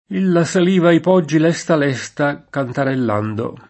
%lla Sal&va i p0JJi l$Sta l$Sta, kantarell#ndo] (Pascoli)